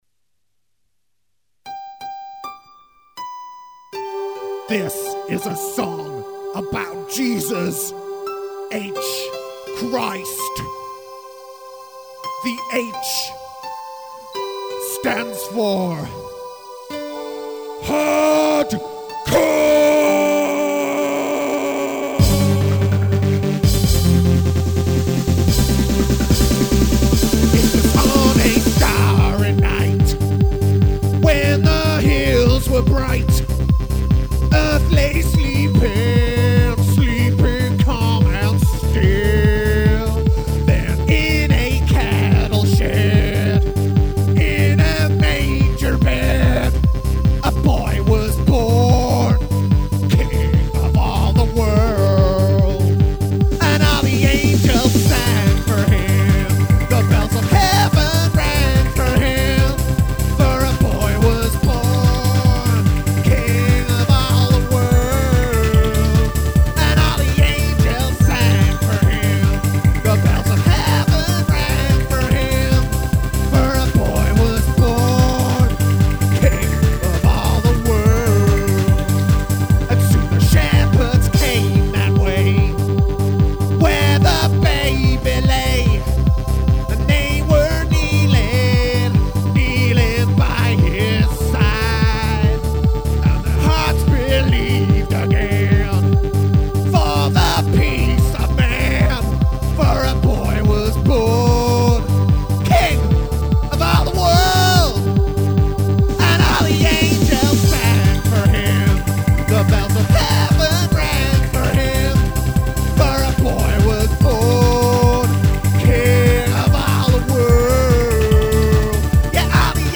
Christmas anthem